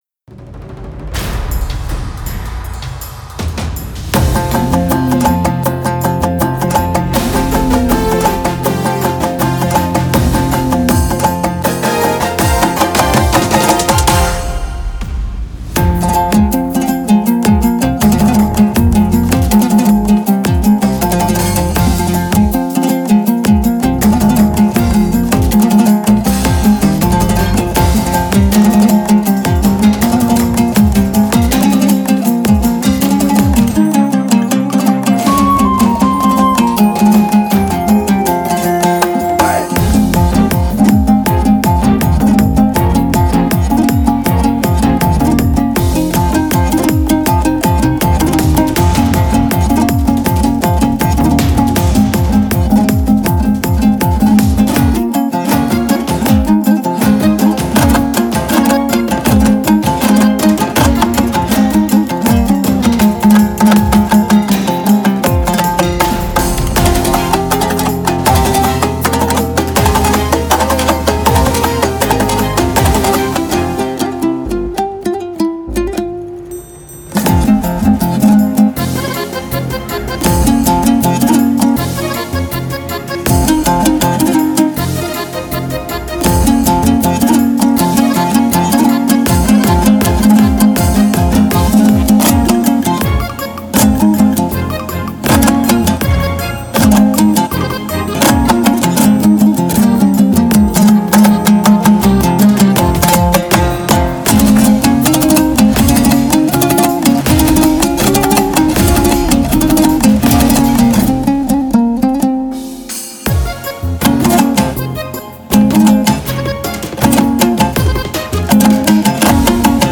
инструментальная композиция
меланхоличное и задумчивое